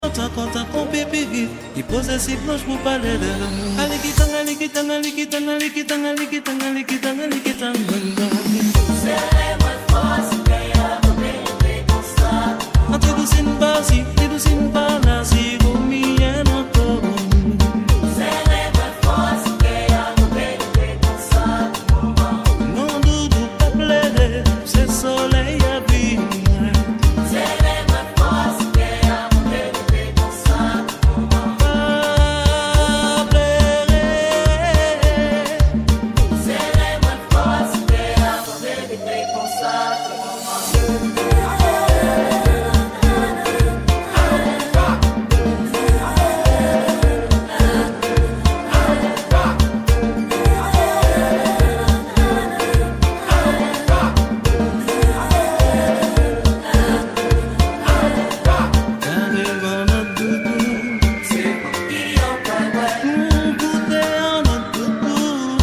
ZOUK REMIX